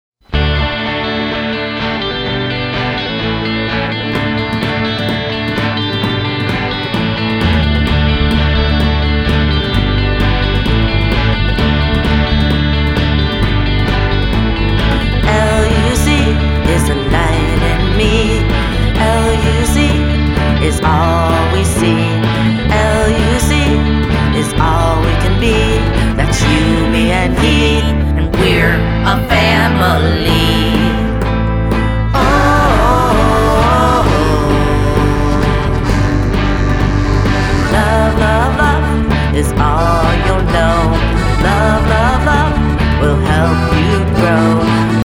recorded in a small recording studio